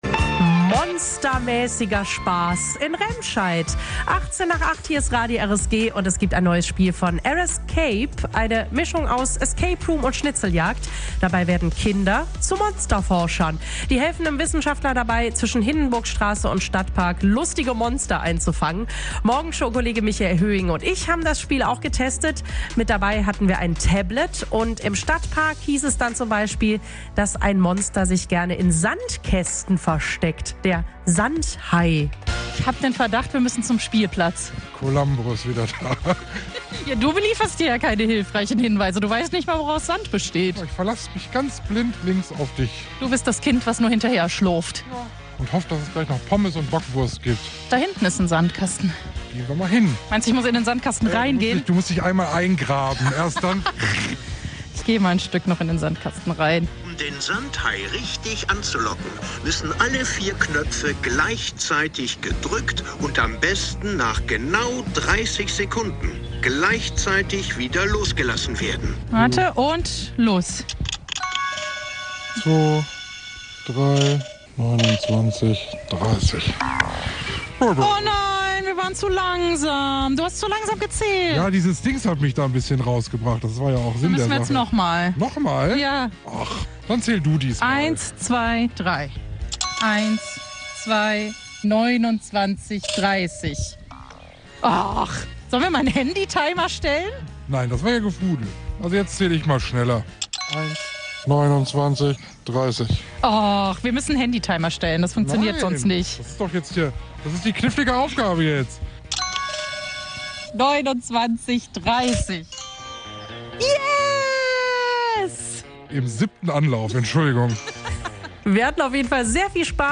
Eindrücke von der Monsterforscher-Tour